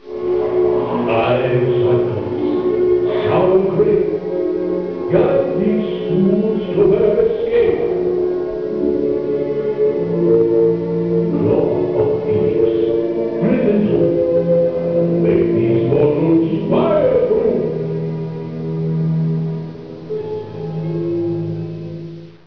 Sounds of Dueling Dragons
Some of the queue music has changed since these recordings were made